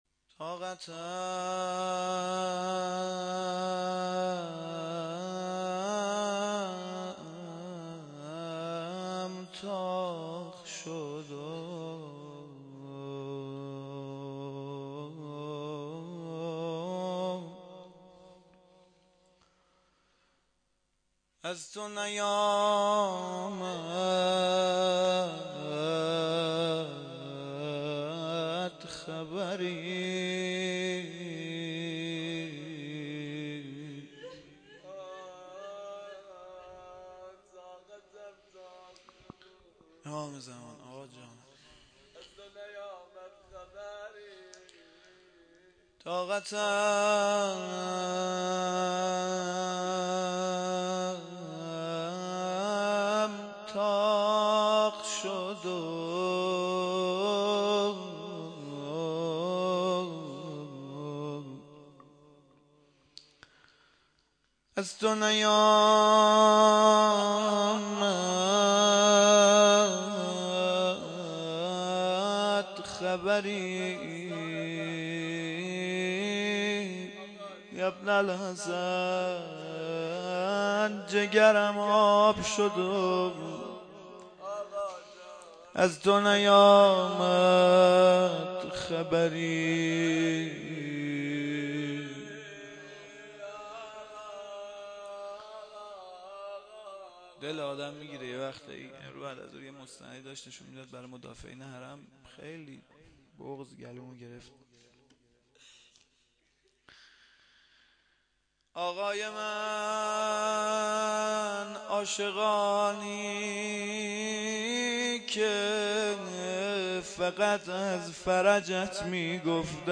شهادت امام علي (ع)-روضه
02-sham-shahadat-hazrat-ali-93-roze.mp3